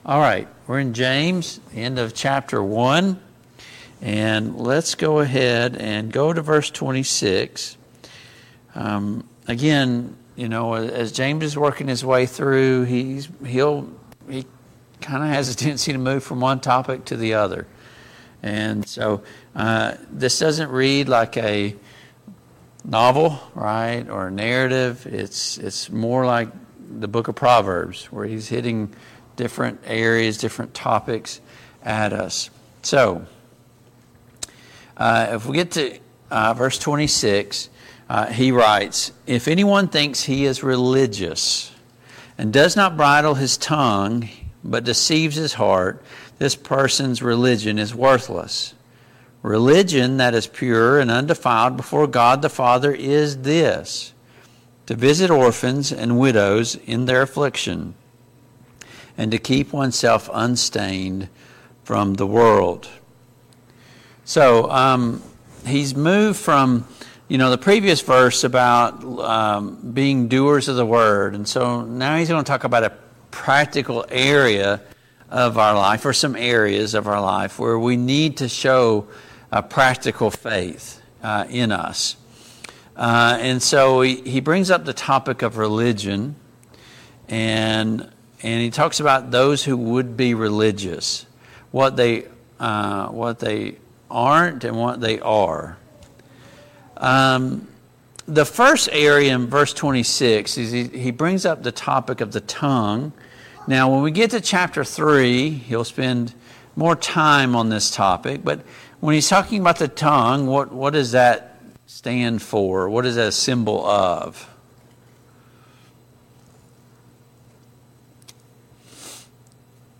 Passage: James 1:26-27, James 2:1-7 Service Type: Mid-Week Bible Study